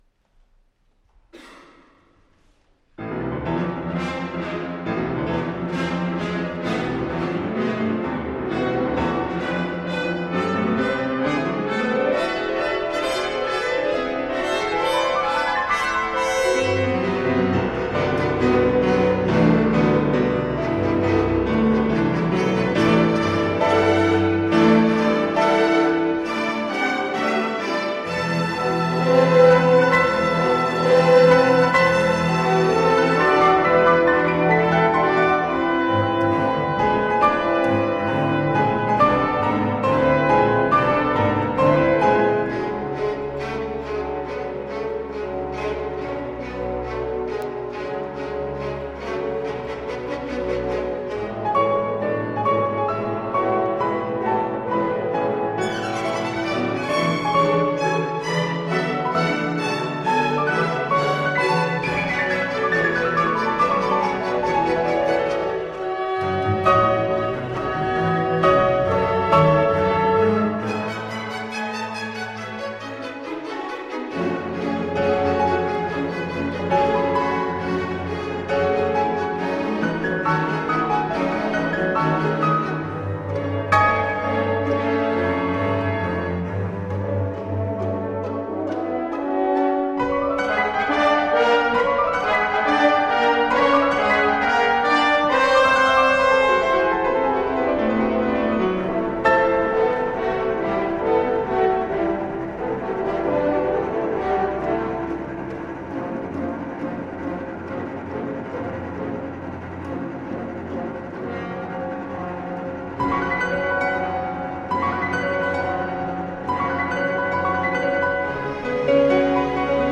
Live recording "Ion Baciu Hall" of Iasi - Romania 2005